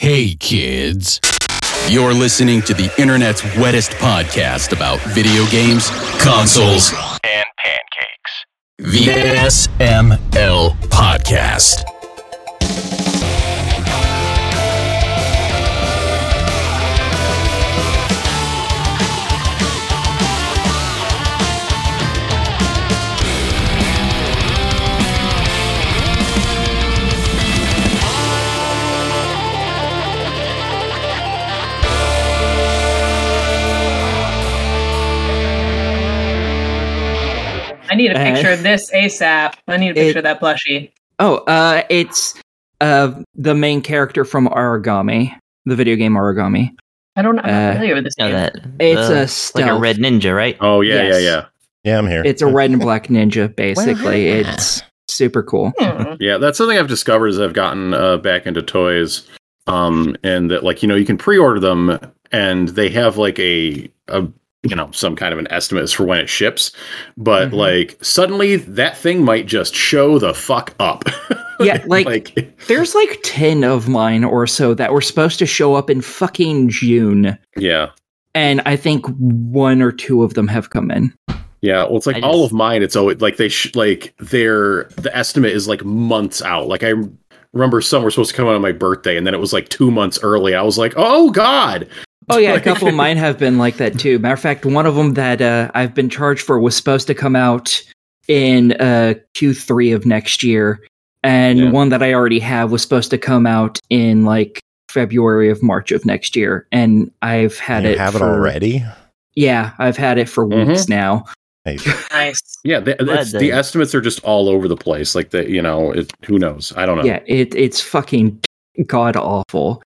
We join this episode in progress because they were already talking when I joined on discord.